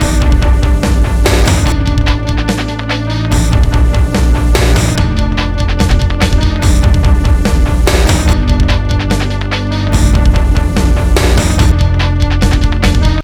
145_pill_D_loop.wav